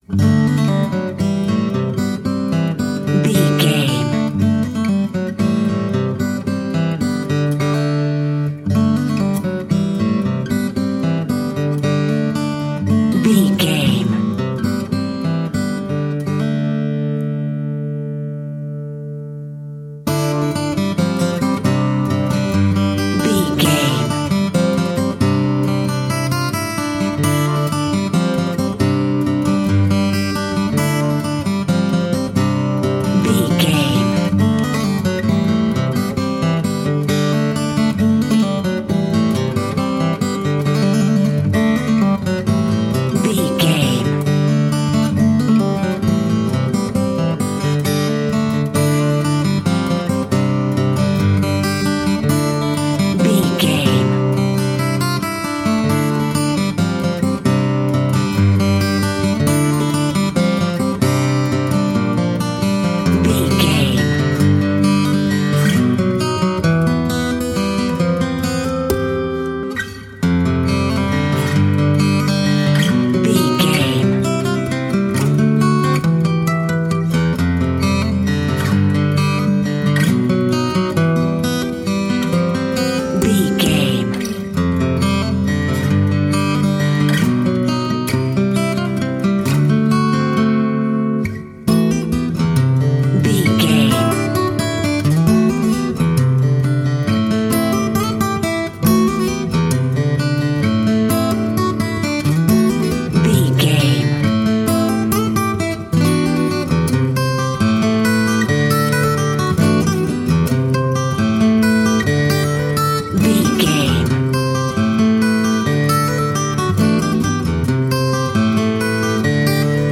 solo acoustic classical guitar track
Uplifting
Ionian/Major
acoustic guitar
quiet
tranquil
soft
soothing